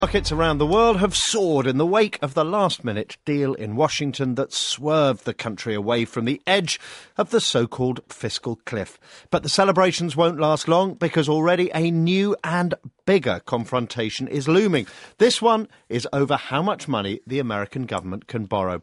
【英音模仿秀】悬崖勒马 迷途未返 听力文件下载—在线英语听力室